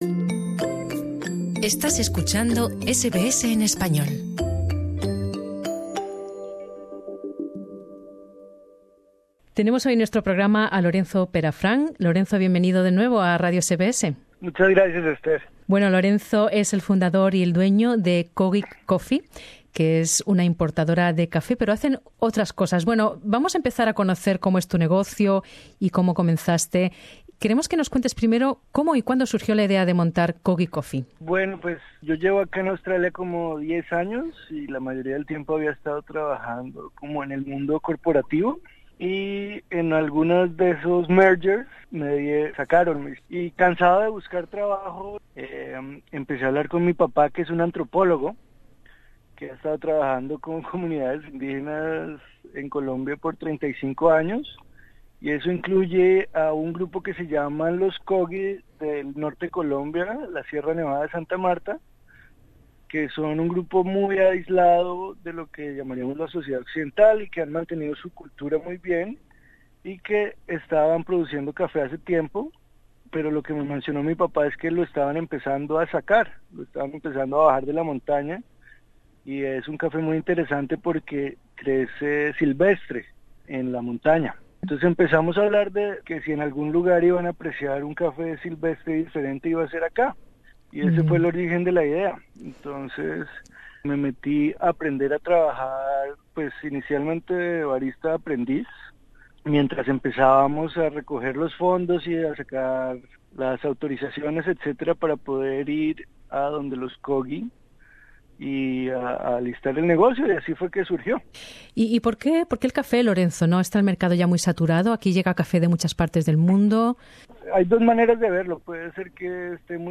Su empresa, Kogi Coffee, vende café cultivado por una comunidad indígena muy especial. Escucha aquí la entrevista.